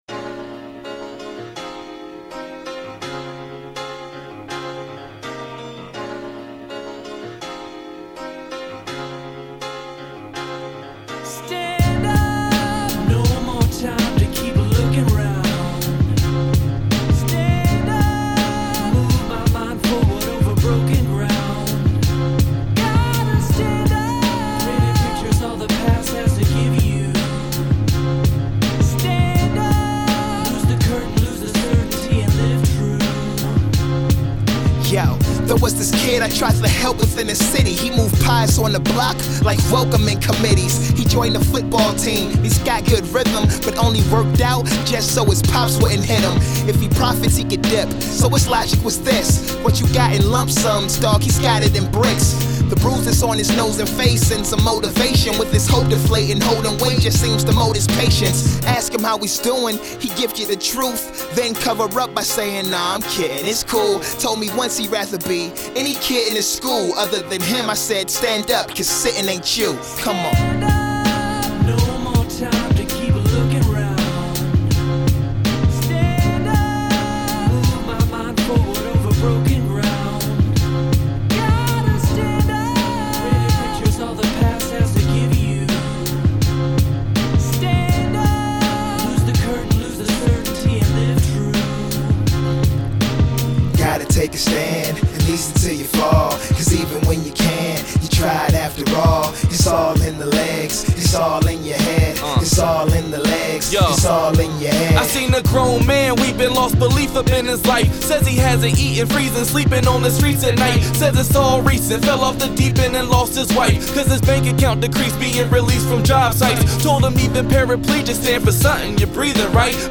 emcee